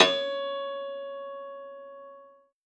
53v-pno07-C3.wav